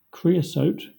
Ääntäminen
Southern England RP : IPA : /ˈkɹiːəˌsəʊt/ US : IPA : /ˈkɹiːəˌsoʊt/